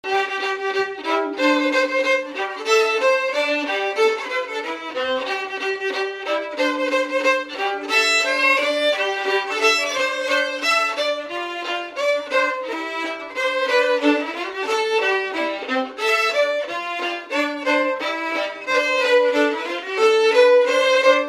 Résumé instrumental
Catégorie Pièce musicale inédite